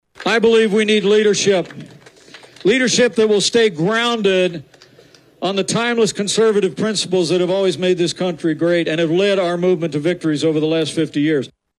(Des Moines) Former Vice President Mike Pence says he’ll “restore civility” to the White House if elected president.  On Thursday, Pence spoke to hundreds of people at the Des Moines Register Political Soapbox at the Iowa State Fair.